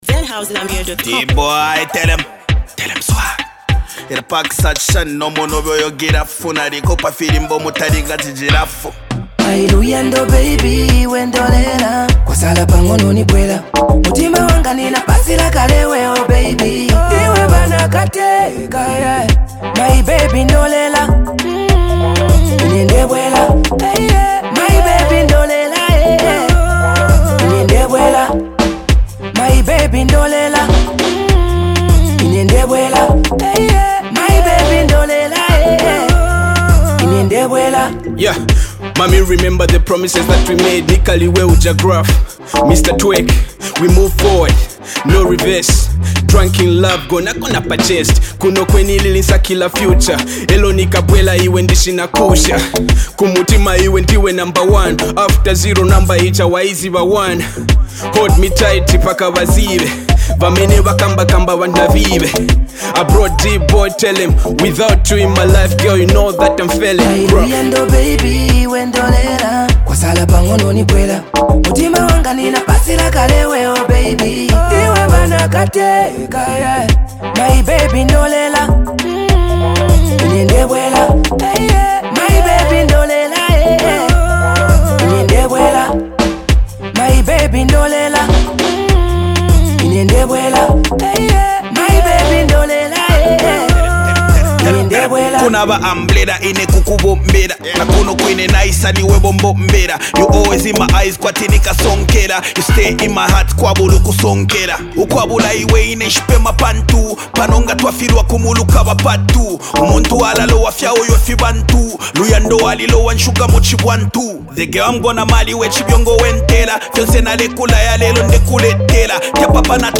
a banger filled with energy and vibes!